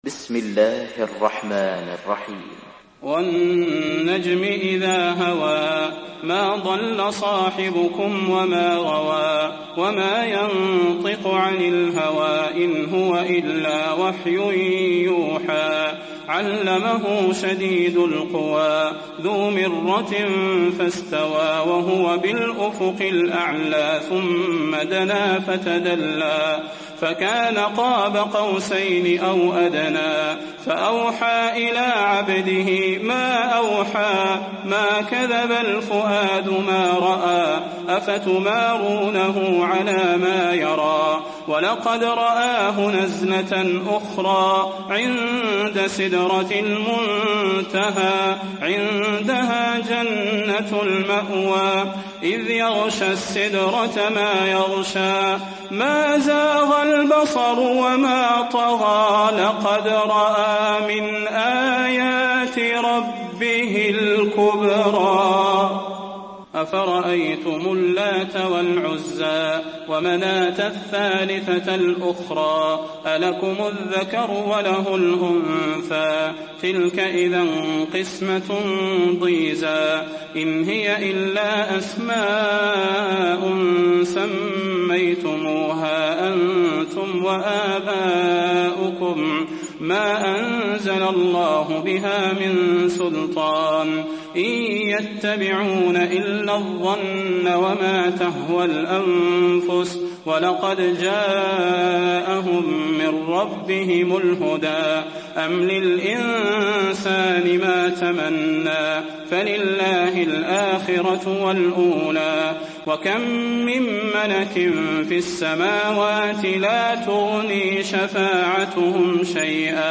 تحميل سورة النجم mp3 بصوت صلاح البدير برواية حفص عن عاصم, تحميل استماع القرآن الكريم على الجوال mp3 كاملا بروابط مباشرة وسريعة